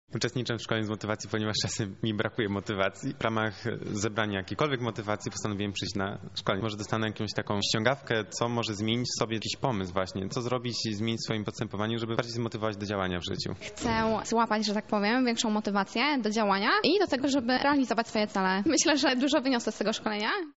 Zapytaliśmy uczestników dlaczego zdecydowali się na takie szkolenie oraz jakie mają oczekiwania.